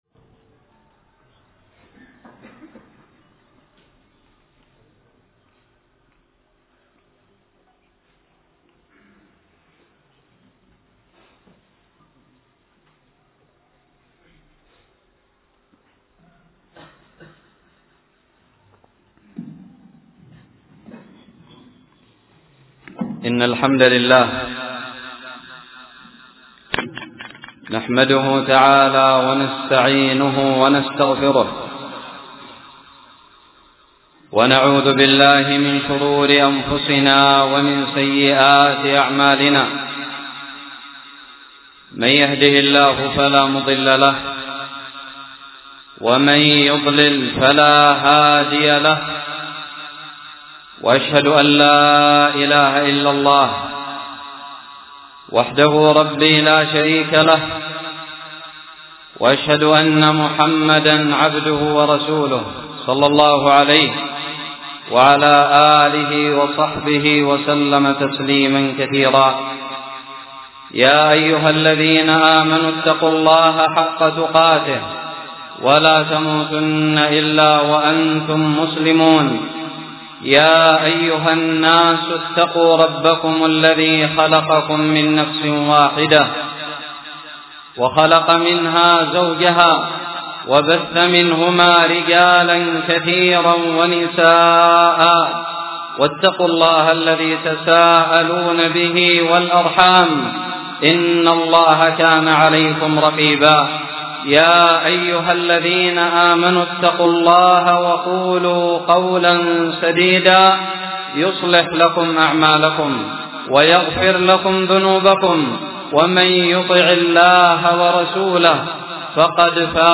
خطب الجمعة
ألقيت بدار الحديث السلفية للعلوم الشرعية بالضالع في 20 جمادى الأولى 1438هــ